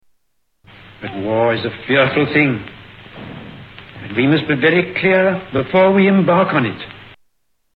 Tags: Historical Neville Chamberlain Audio Neville Chamberlain Speeches Arthur Neville Chamberlain Neville Chamberlain Sounds